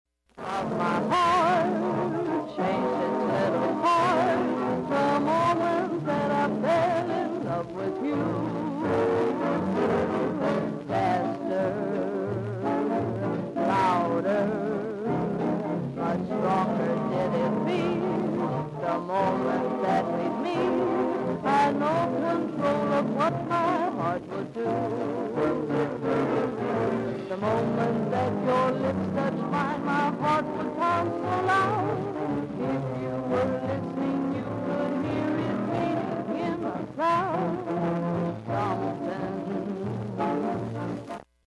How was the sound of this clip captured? I just posted to eBay a c.1929 Columbia-Kolster magnetic reproducer to eBay: I hooked it up to my computer and recorded a segment from a 78 rpm record using a steel needle: